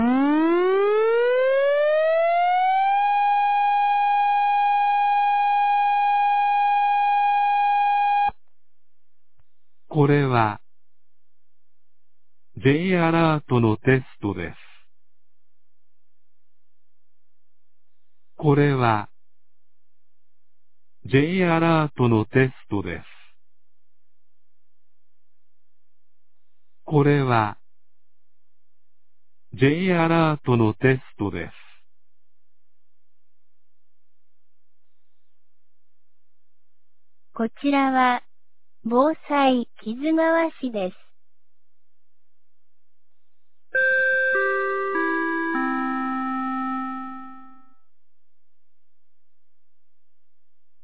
2024年05月22日 11時01分に、木津川市より市全域へ放送がありました。
放送音声